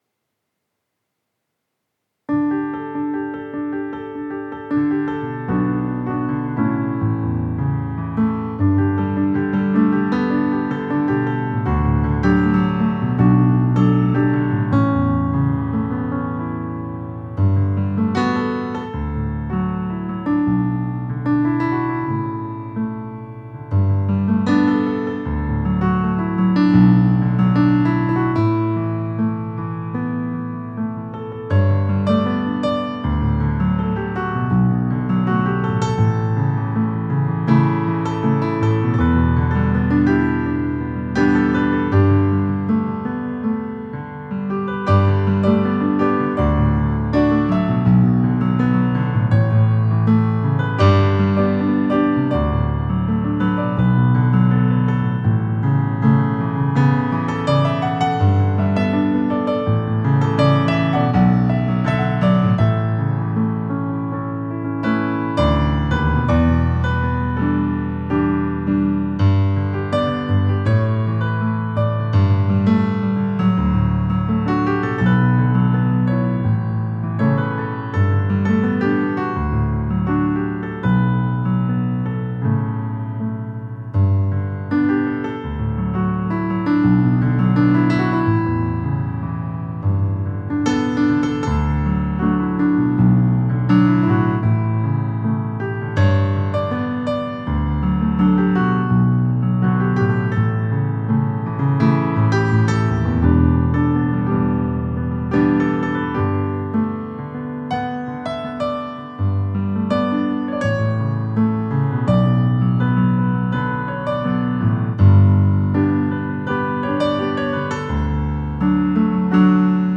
Acoustic Piano Cover